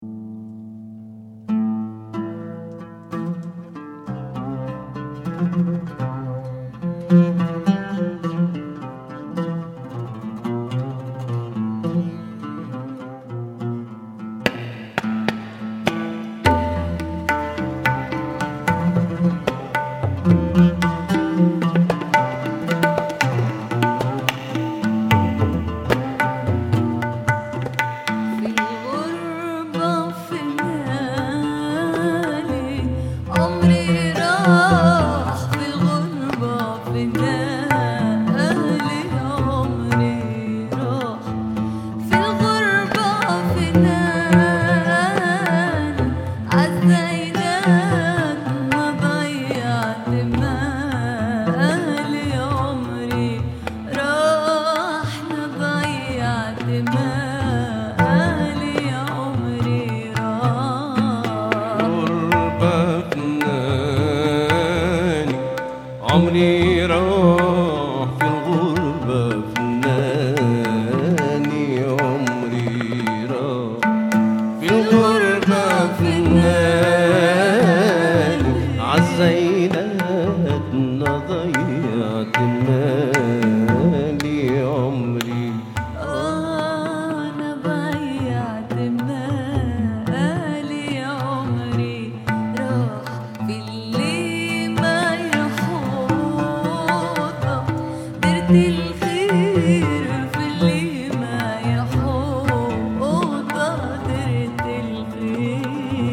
chant
oud